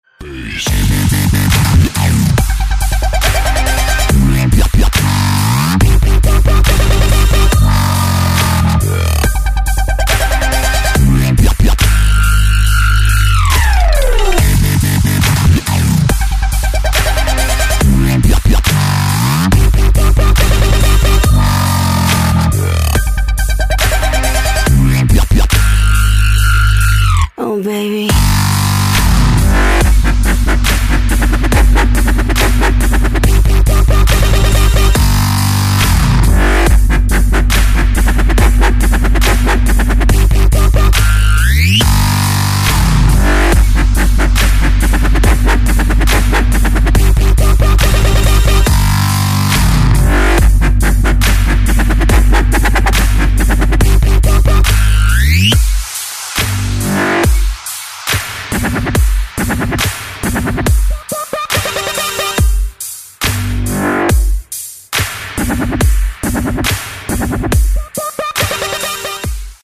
• Качество: 128, Stereo
мужской голос
громкие
жесткие
женский голос
Electronic
EDM
электронная музыка
Trapstep